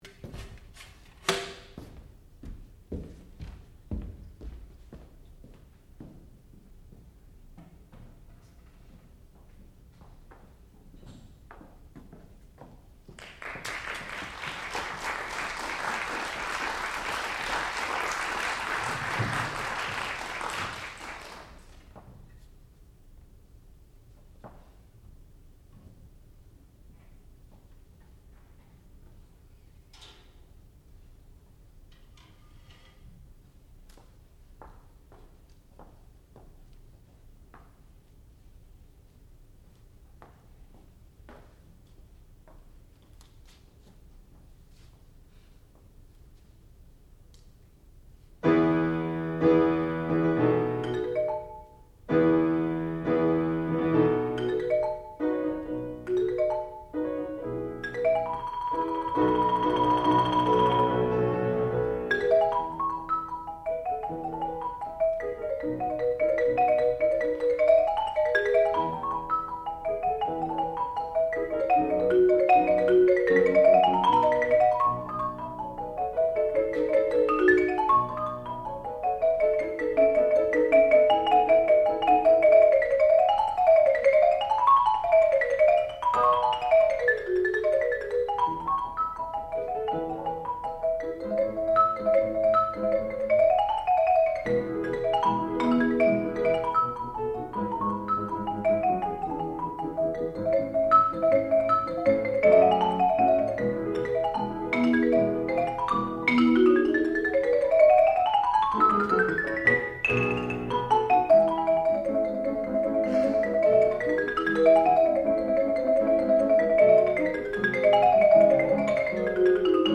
sound recording-musical
classical music
percussion
piano
Junior Recital